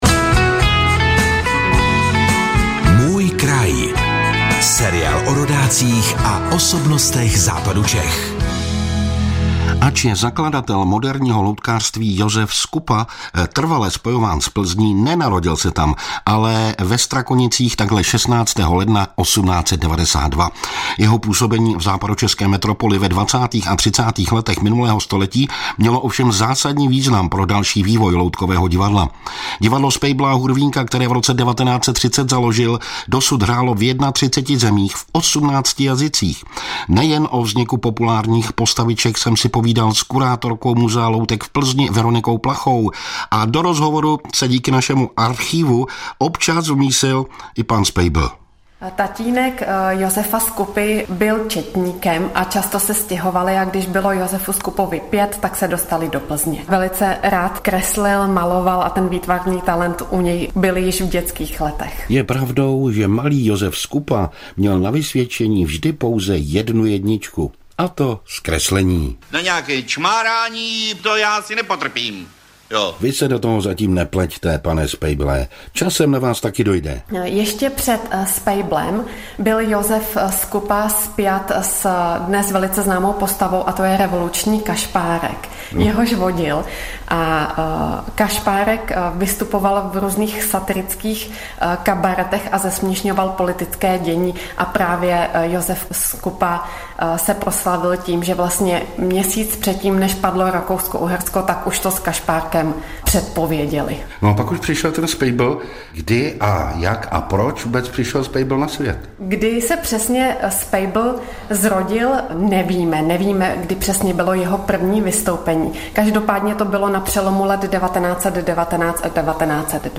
Náš host: Hostem Miroslav Kroc, starosta obce Břasy - 24.06.2024